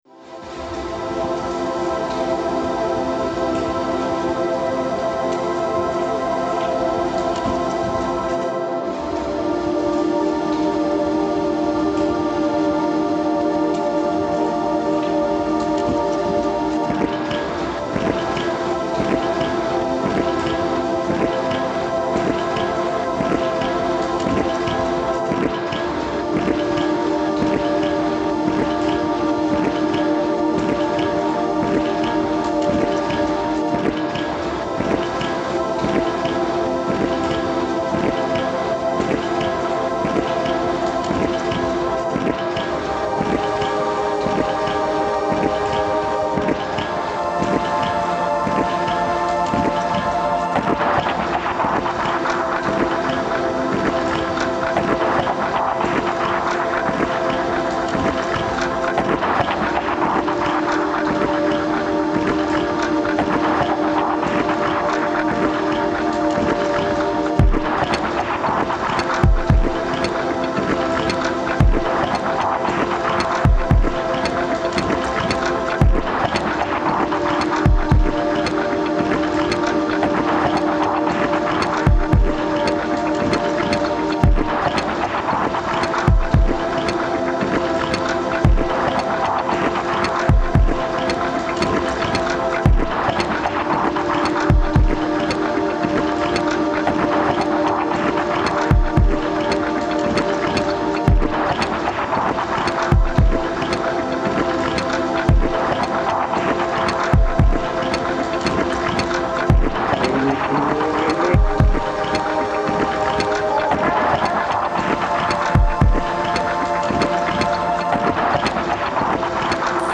The site had an overcasting ambient background sound which consists of general traffic noises.
The sounds were recorded with a Tascam DR-2d Field Recorder which captured the richness of the auditory qualities such as the expansion joints driven by cars, the wind blowing through the tunnels and driving dry leaves to scrape over the concrete floor, rustling leaves in the tree, muffled conversations inside the tunnel, birds tweeting, etc. These clips gave us an unfamiliar yet unique insight into the world of the sounds which is usually not easily detectable through a visual media such as an image.
The other audio production exercise was making a soundtrack in Ableton Live using the essence of the sounds recorded on site, to illustrate the site conditions in an audible language.
The rhythm of the soundtrack was determined in the way that it would reflect the rhythmic pace of the site under the traffic pressure in daytime. The pad, consisting of several individual notes, were the identifiable added ingredient which made the soundtrack a piece of cohesive audio work.
hockley-underpass2.mp3